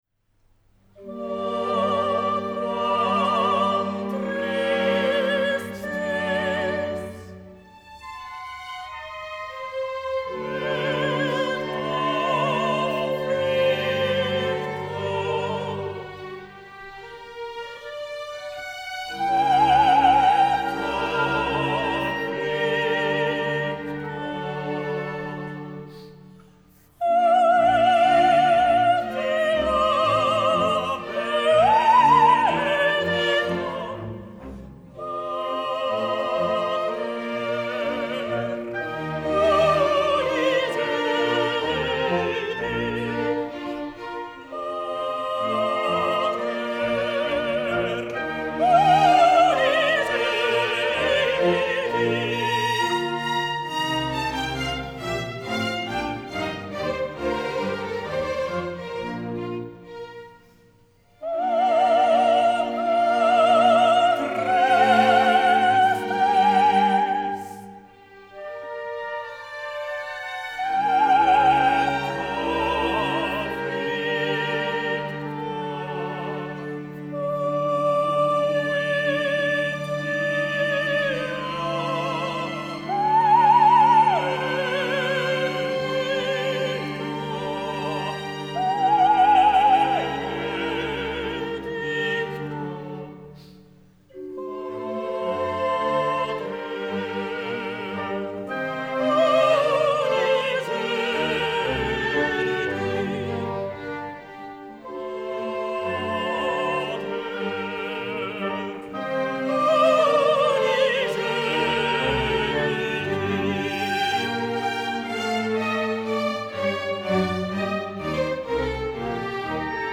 coll'aggiunta de' stromenti a fiato
soprano
basso